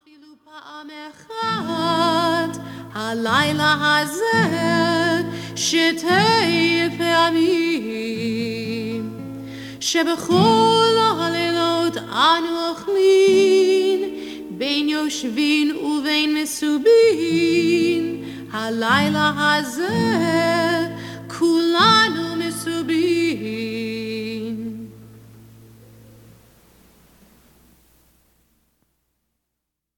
A Jewish sing-a-long for families!